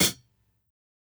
Closed Hats
HIHAT_GROWTH.wav